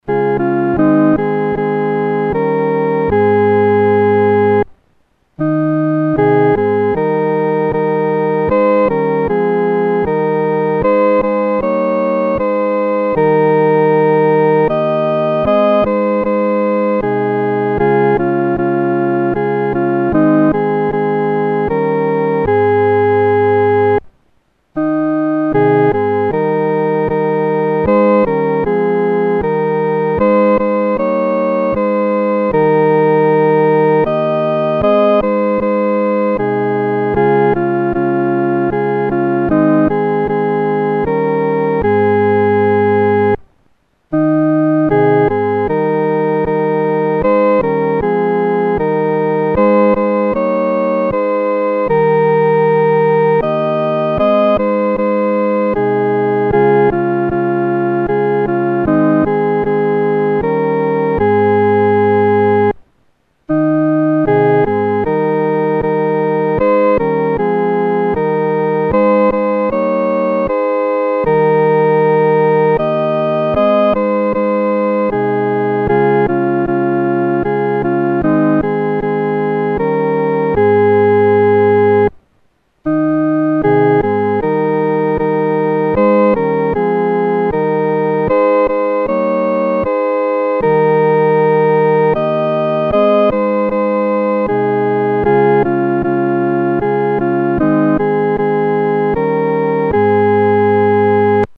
独奏（第一声）
万口欢唱-独奏（第一声）.mp3